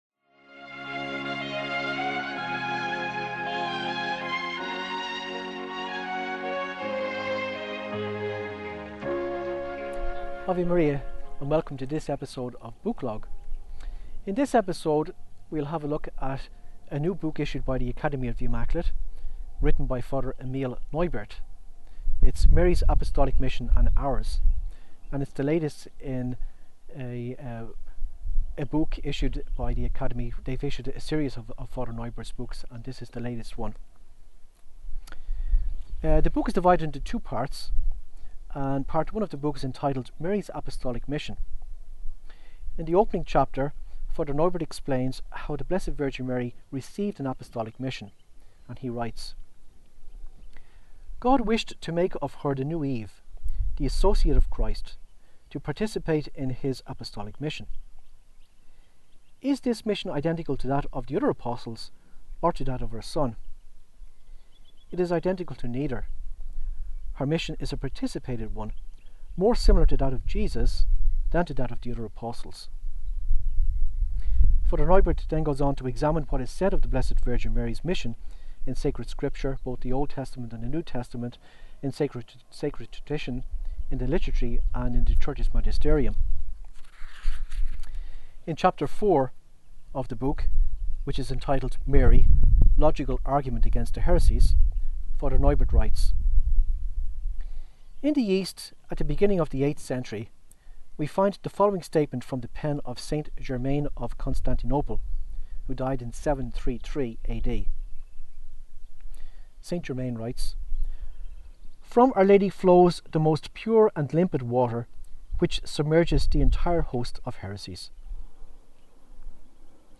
This book is very useful for anyone involved in a Marian apostolate, whether he be priest, religious or layman. For those who are interested, this video was shot on location at the  Wungong Dam (just outside the city of Perth, Australia).